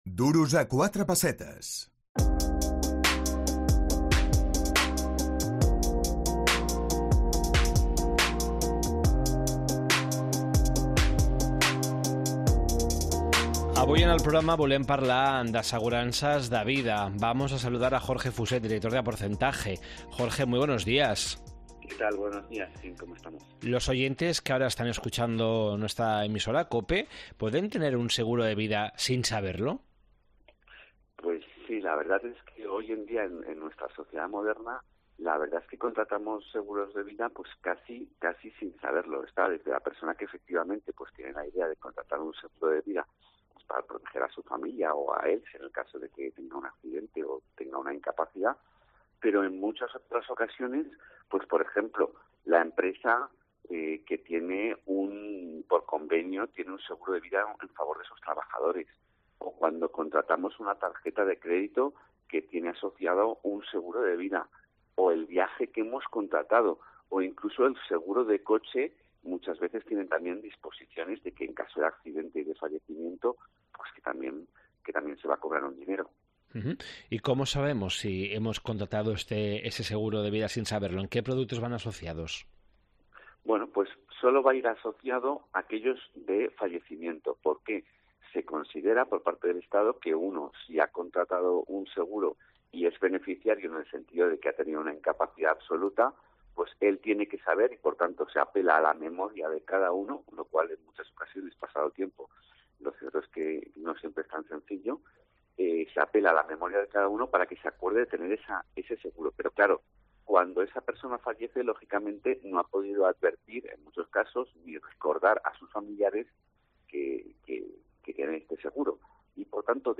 Entrevista a